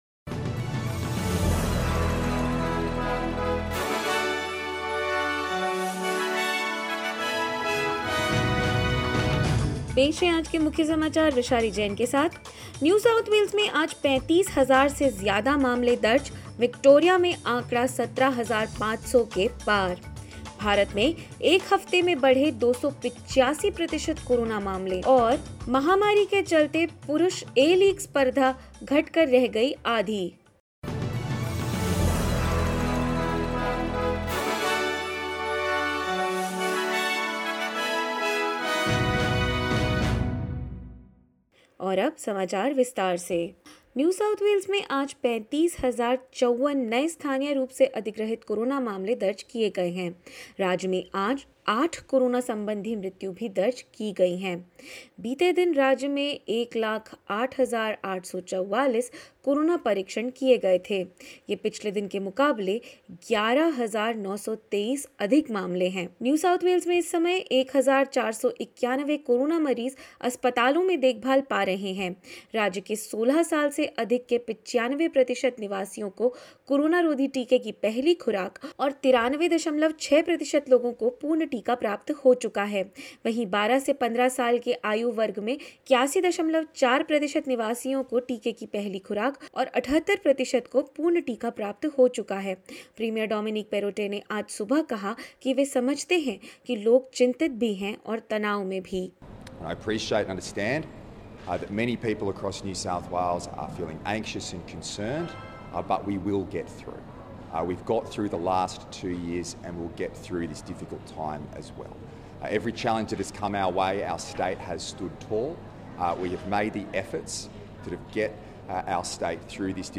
In this latest SBS Hindi news bulletin: Daily cases in NSW cross 35,000, Victoria registers 17,636 infections; PM Scott Morrison plans rebates on RAT kits for low income groups and free kit distribution at testing centres ahead of cabinet meeting and more.